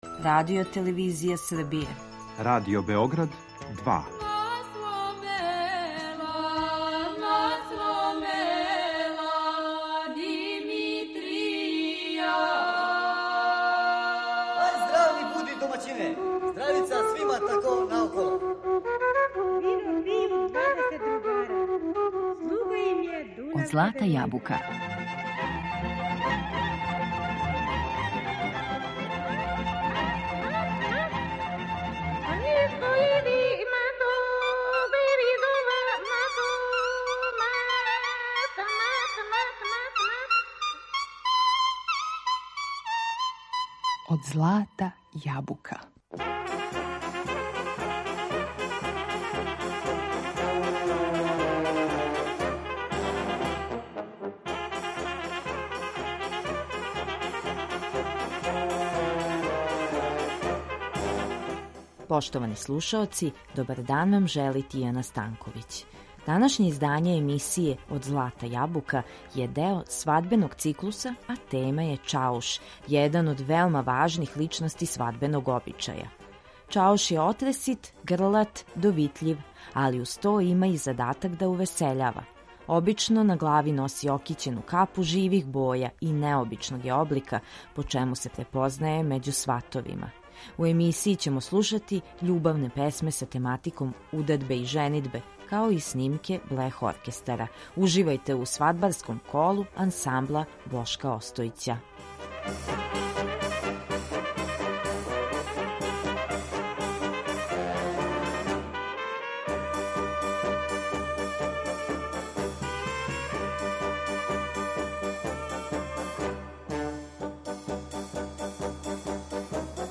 У емисији ћемо слушати љубавне песме са тематиком удадбе и женидбе, као и снимке блех оркестара.